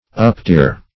uptear - definition of uptear - synonyms, pronunciation, spelling from Free Dictionary Search Result for " uptear" : The Collaborative International Dictionary of English v.0.48: Uptear \Up*tear"\, v. t. To tear up.